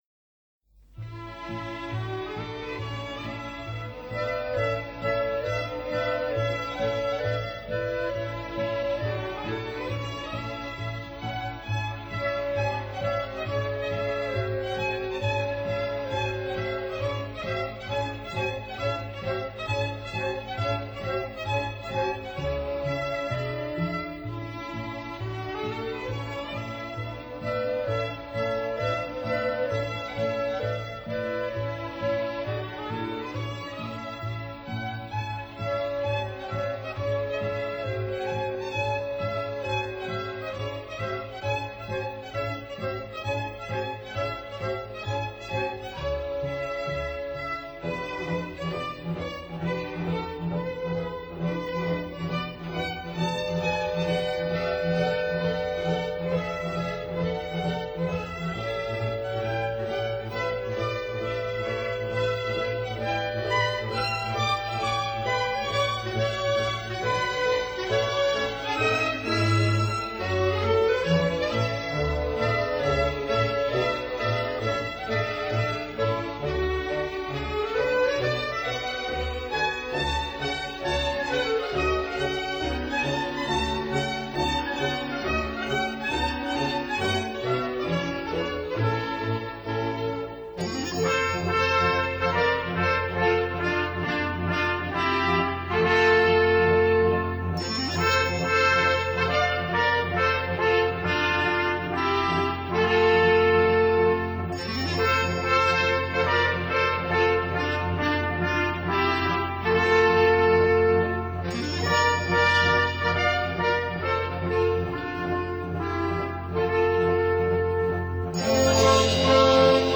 recorded for Finlandia Steamship Company in 1967